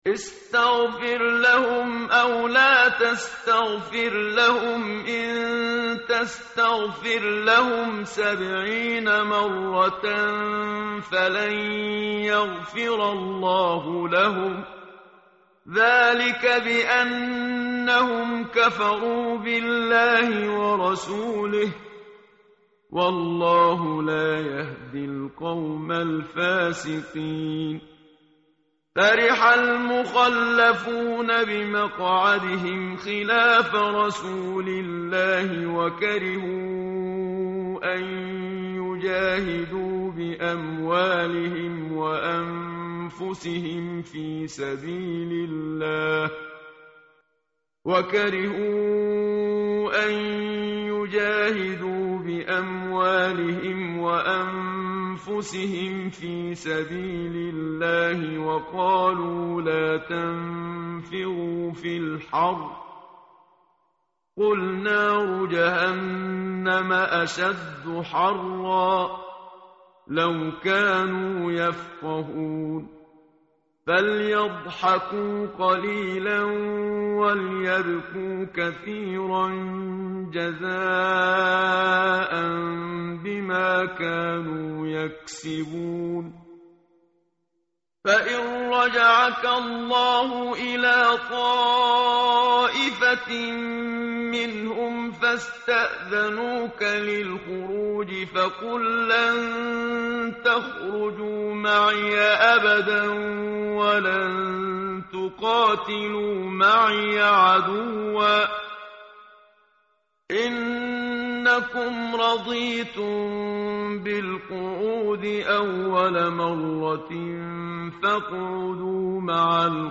tartil_menshavi_page_200.mp3